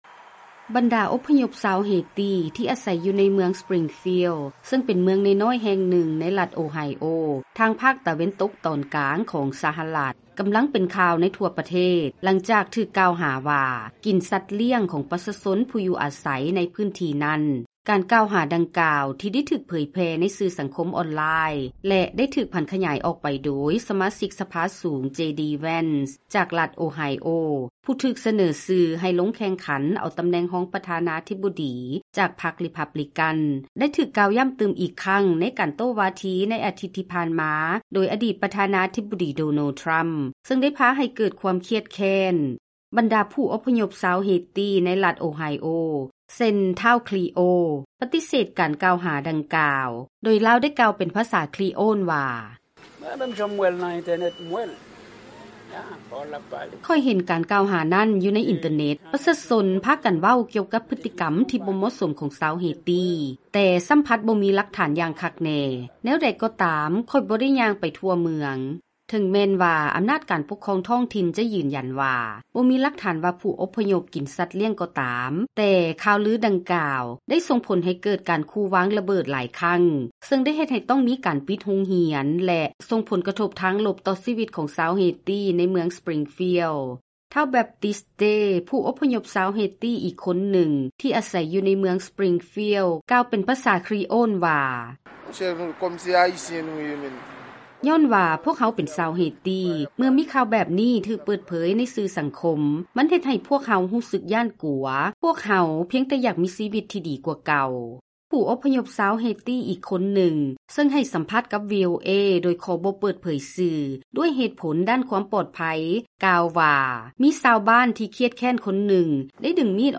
Haitian Migrant ((In Creole))